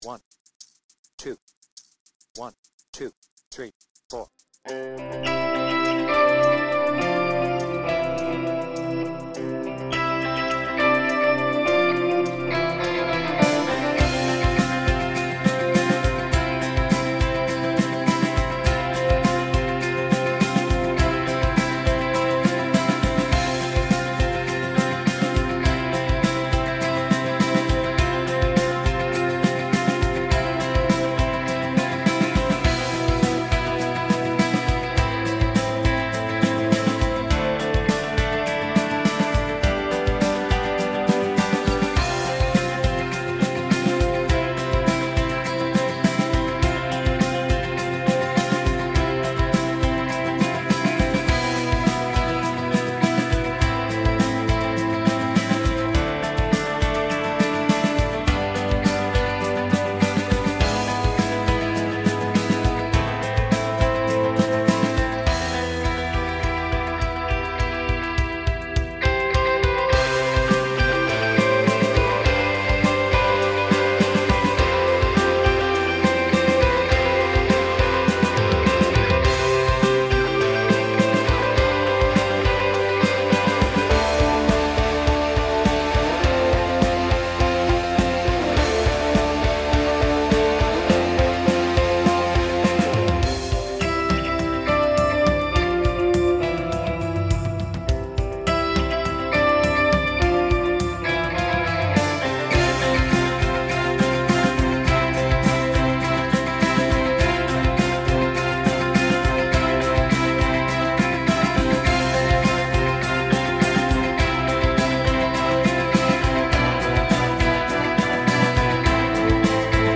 BPM : 103
Tuning : E
Without vocals
Based on the album version